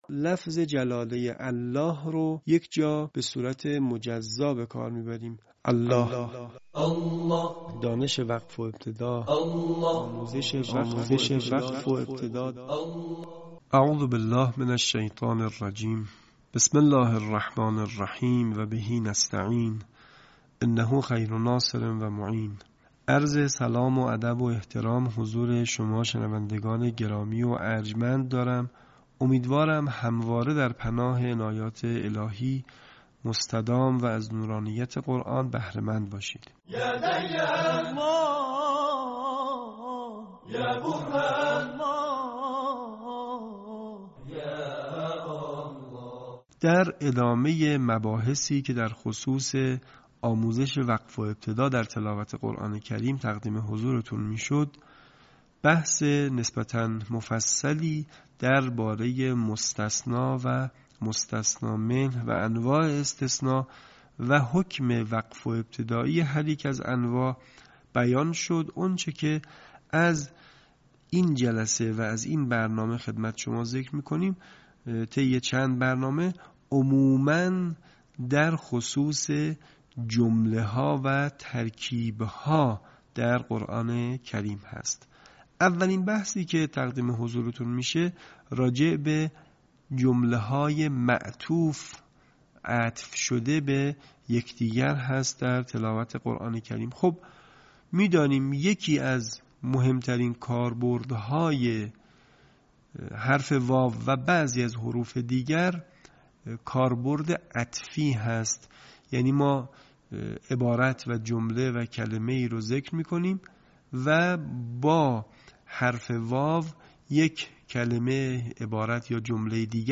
صوت | آموزش وقف و ابتدا در جمله‌های معطوف